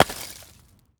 stone break.wav